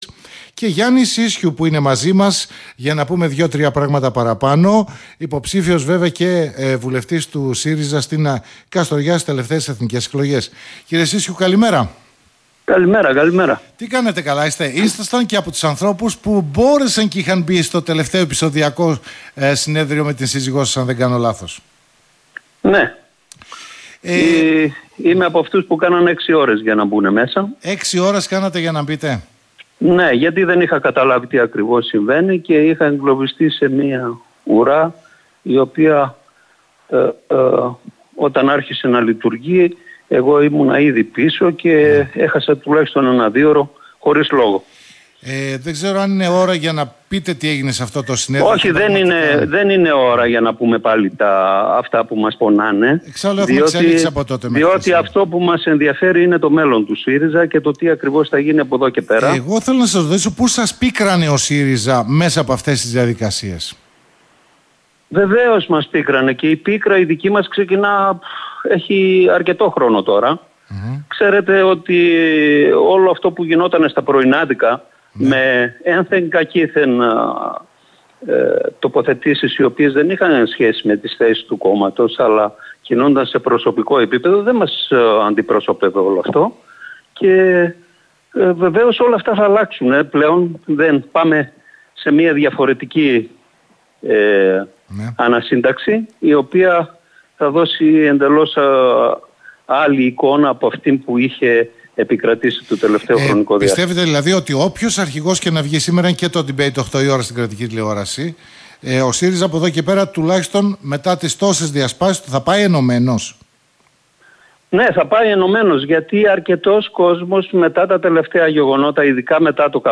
(συνέντευξη)